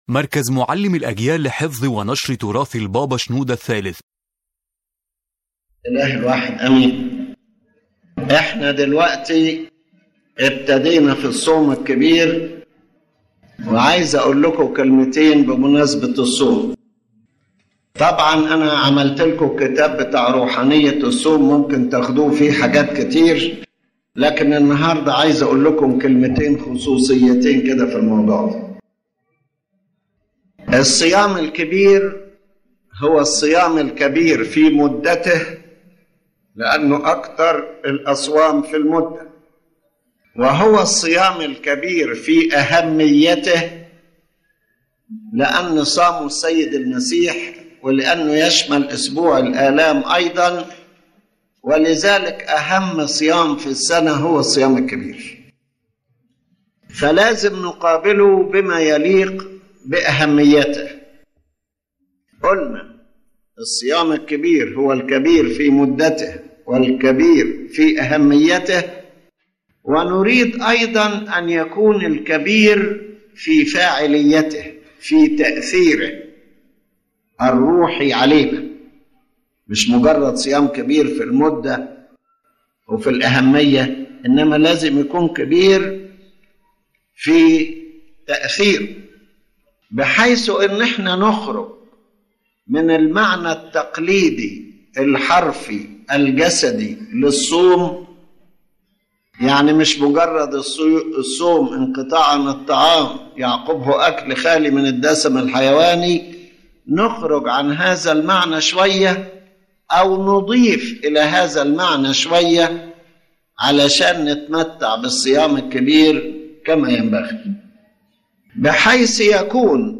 n this lecture His Holiness Pope Shenouda III affirms that the Great Lent is the most important spiritual period of the year because it is long and connected to the Lord’s sufferings, and it should not be limited to physical abstinence from food but rather be a distinguished and holy period consecrated to God.